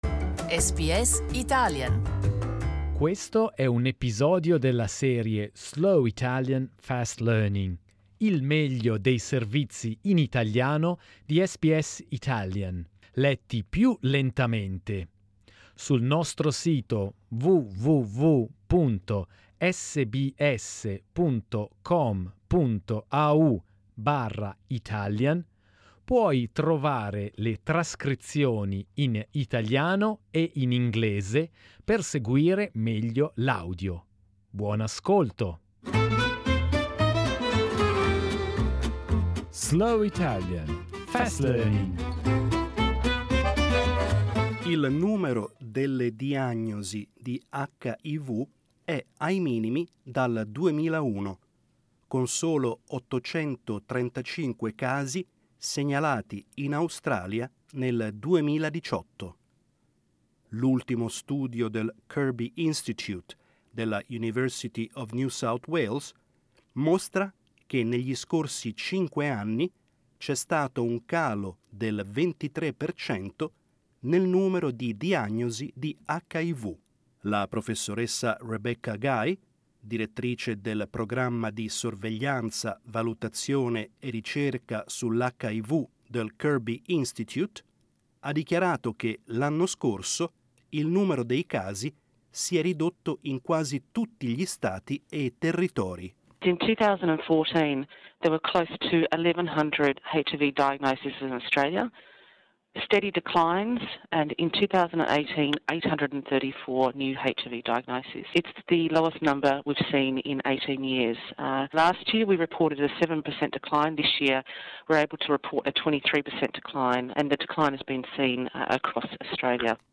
Slow Italian, Fast Learning , il meglio dei nostri servizi della settimana, letti più lentamente e più scanditi , con i testi in italiano e in inglese .